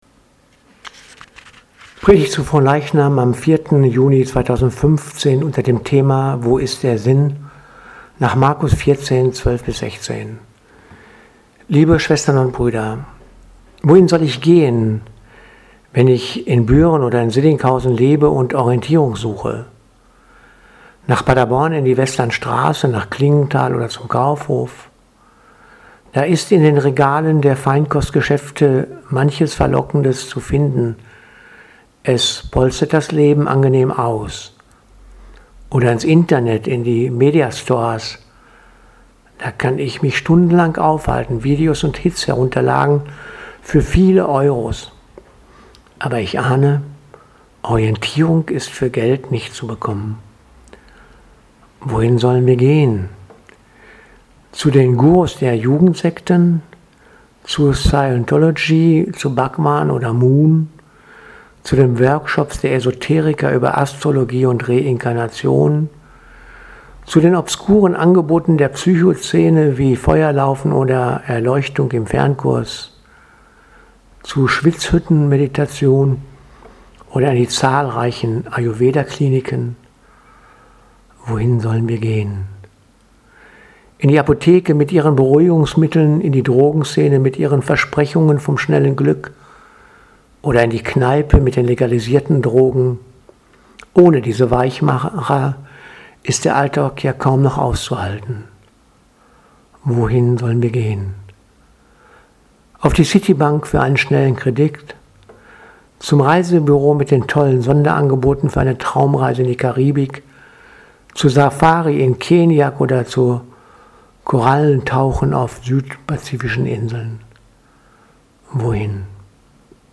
Predigt zu Fronleichnam 4.6. 2015